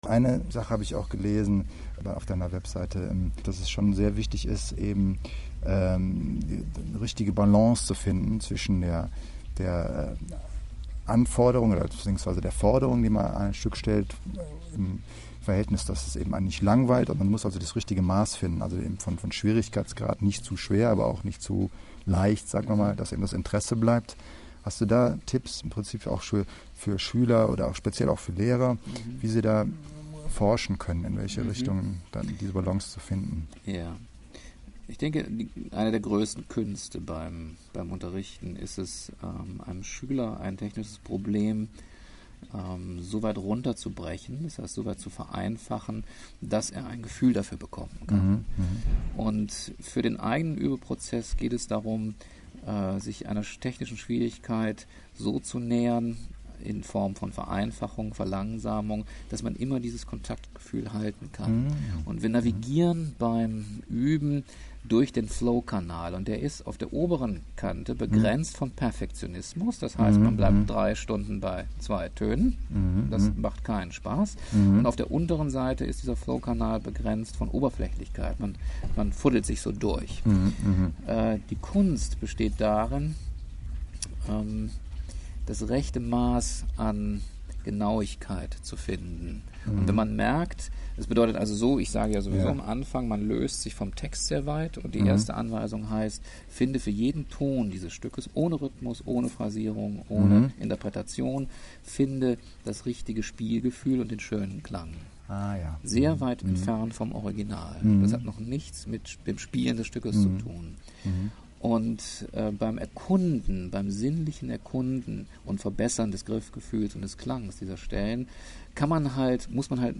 Bericht vom Seminar & Audio Interview